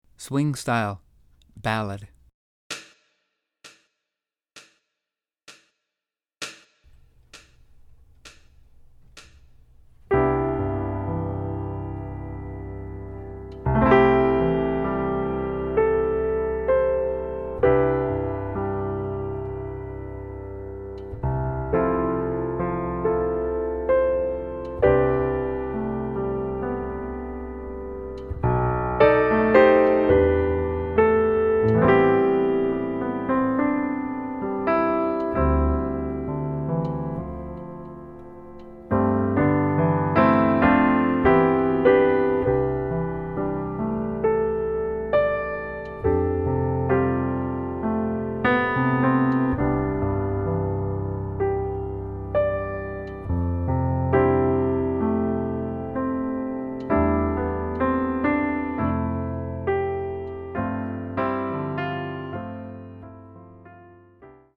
Swing
31. ballad
Yamaha C7 grand piano (not an electronic imitation)
* Every track is introduced with tempo set-up